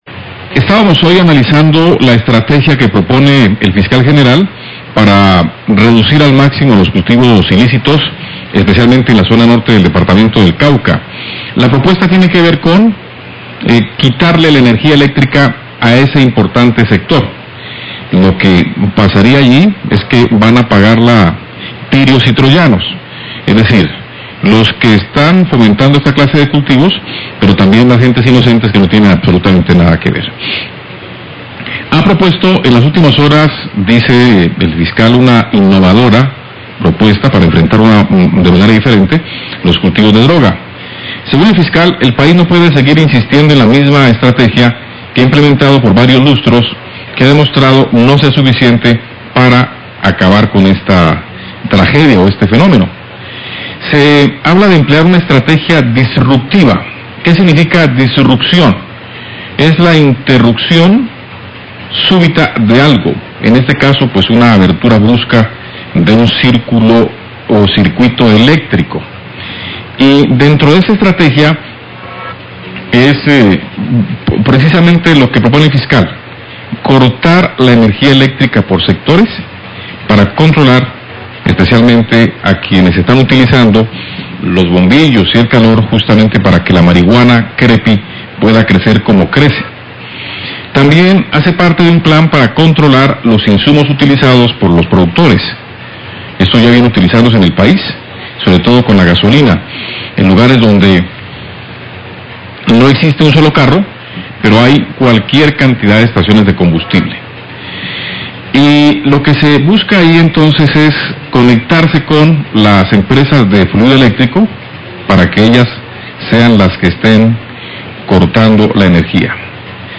Radio
editorial